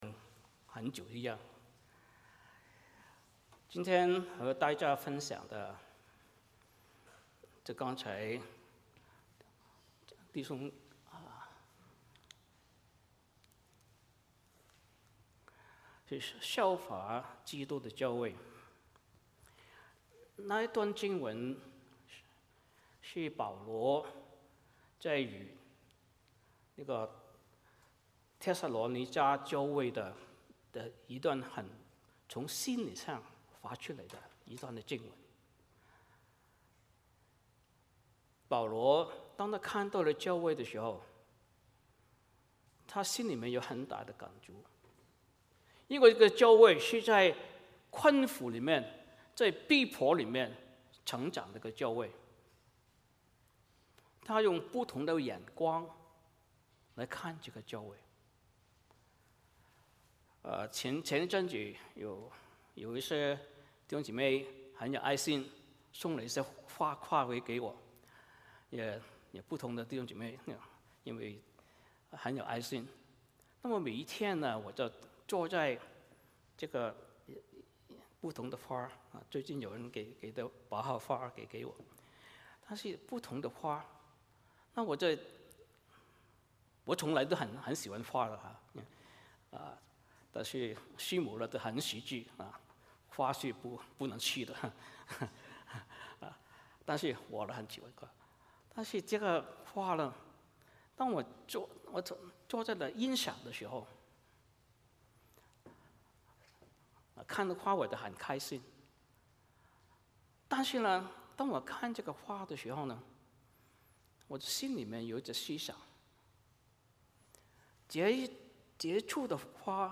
帖撒罗尼迦前书 2:13-20 Service Type: 主日崇拜 欢迎大家加入我们的敬拜。